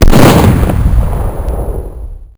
SHOTGUN7.wav